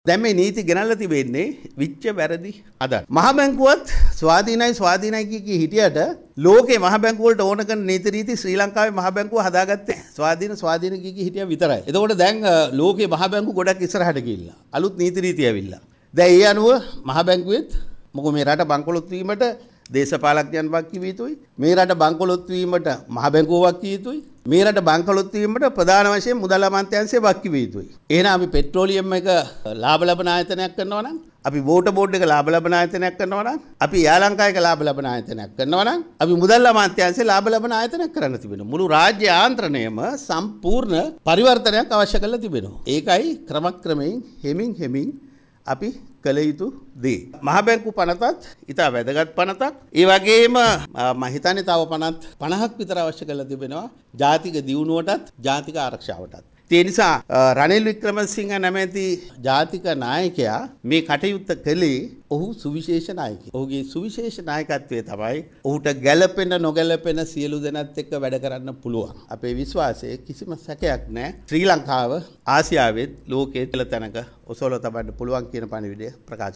ඔහු මේ බව කියා සිටියේ ස්ථාවර රටකට සැවොම එක මඟකට යන මැයෙන් ඊයේ ජනාධිපති මාධ්‍ය කේන්ද්‍රයේ පැවති ප්‍රවෘත්ති සාකච්ඡාවට එක්වෙමින් .